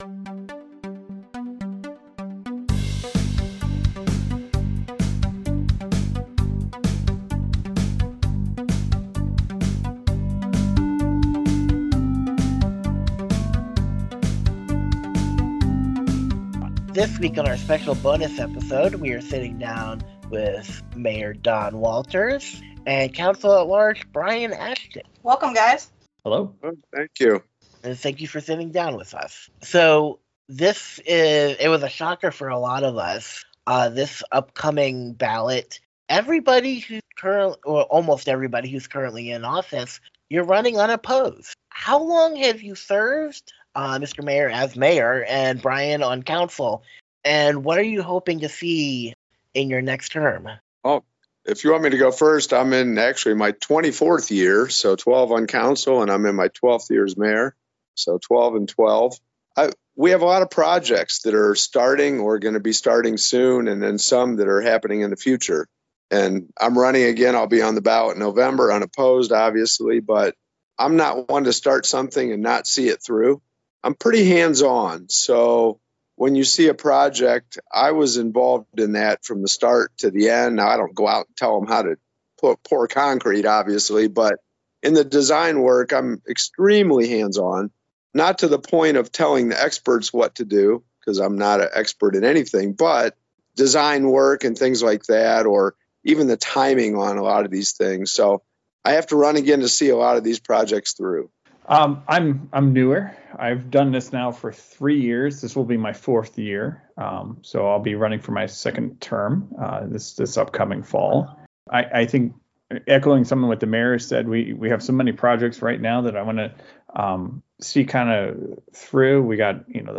Fallscast Bonus Interview: Mayor Don Walters & Council-at-Large Brian Ashton